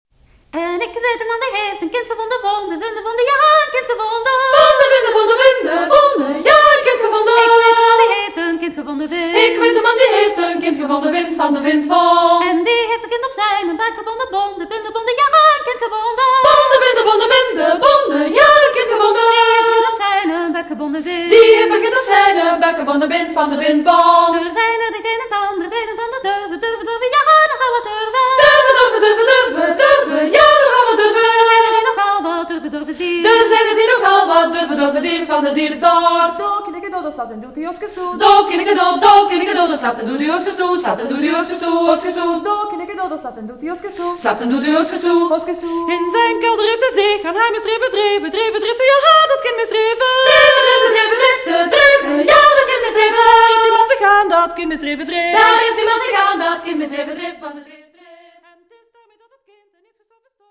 Mittels einer sehr persönlichen, "volkstümlichen" Sprache, mittels der Bewegung, der Musik und des Schweigens versuchen sie ihre Empfindungen nach der Affaire Dutroux zum Ausdruck zu bringen.